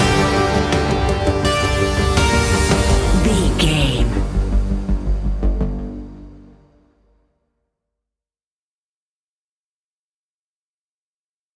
In-crescendo
Aeolian/Minor
ominous
eerie
suspenseful
electronic music
Horror Pads
Horror Synths